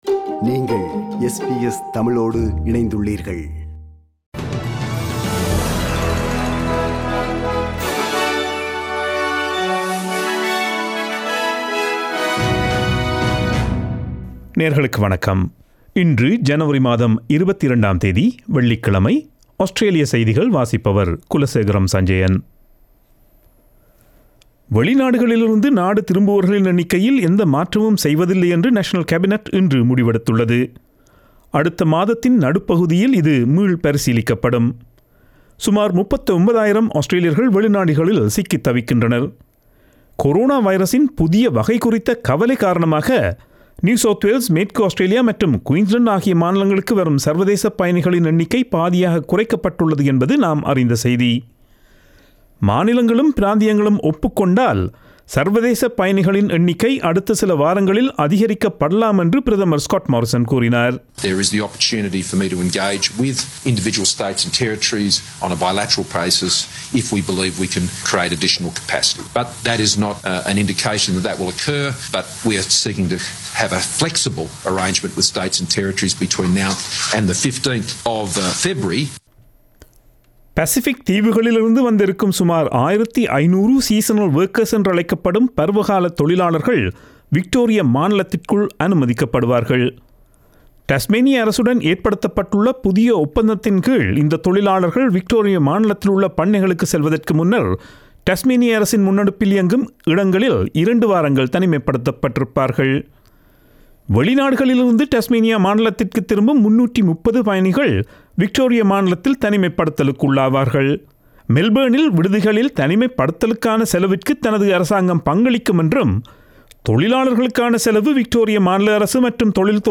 Australian news bulletin for Friday 22 January 2021.